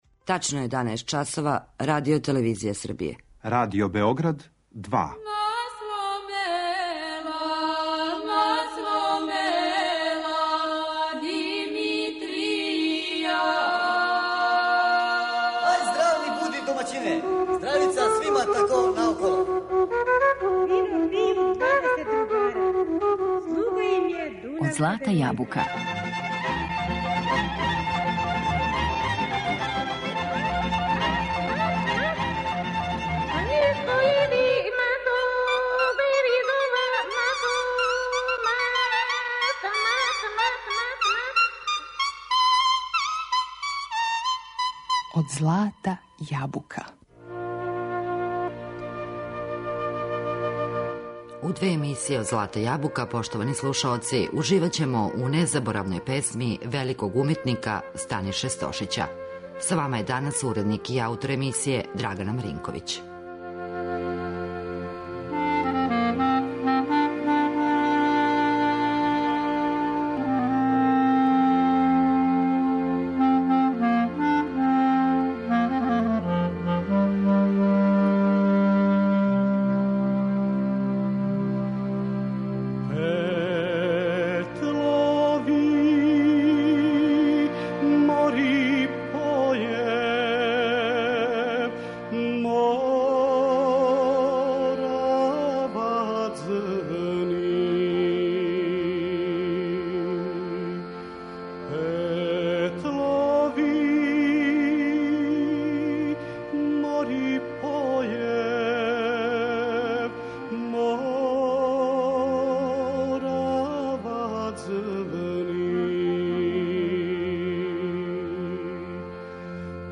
музички портрет